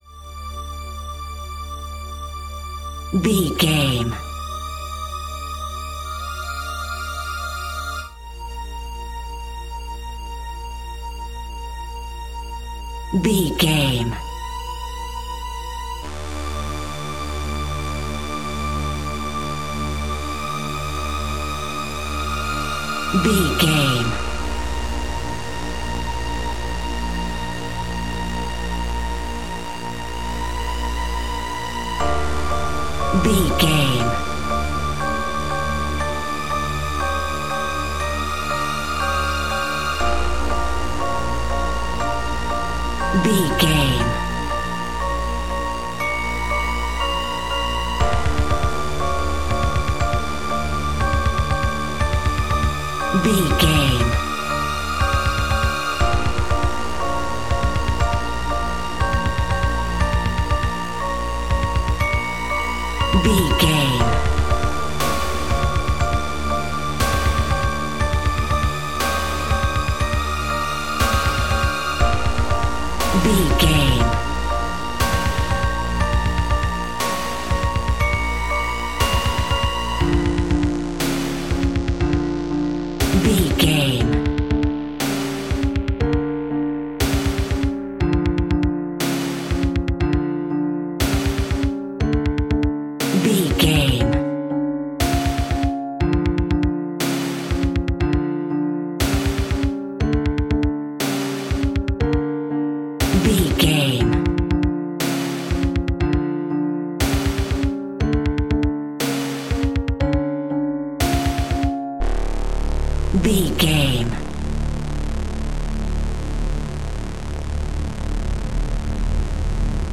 Eighties Horror Movie Music Soundtrack.
Phrygian
D
scary
ominous
dark
suspense
eerie
piano
synthesiser
drums
pads
eletronic